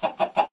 chicken3.ogg